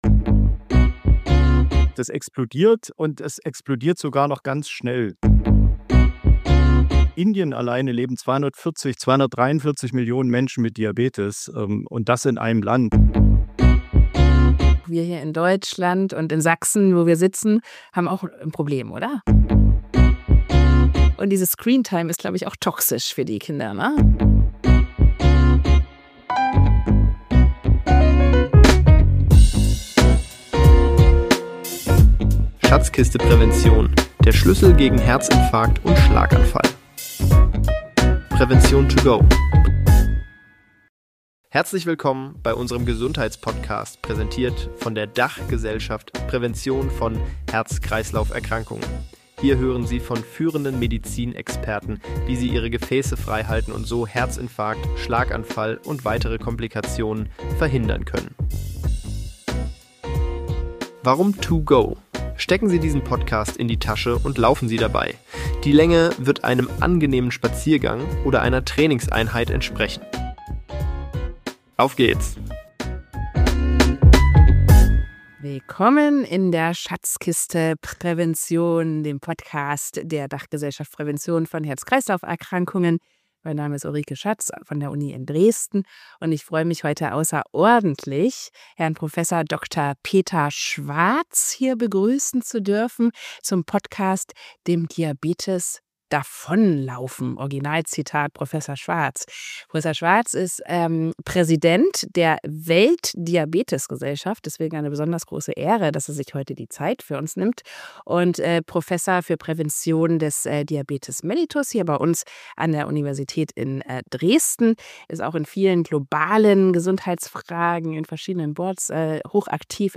Im Gespräch: